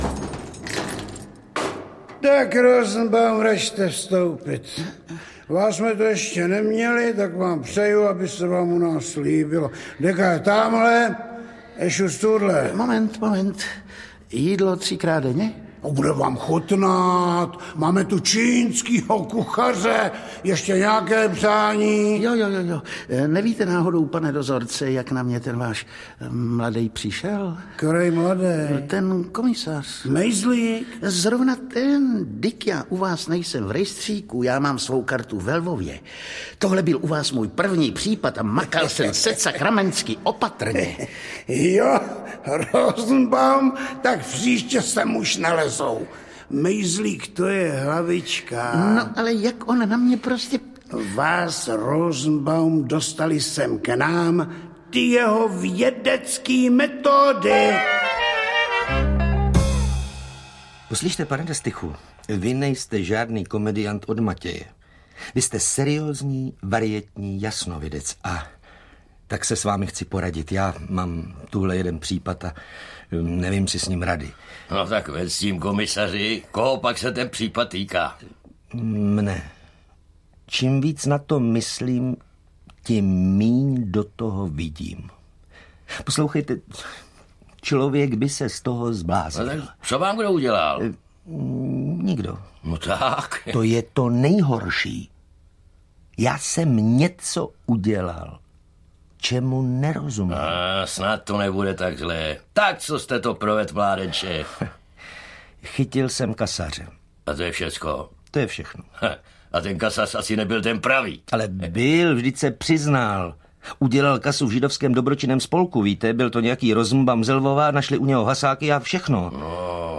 Výběr z povídek Karla Čapka audiokniha
Ukázka z knihy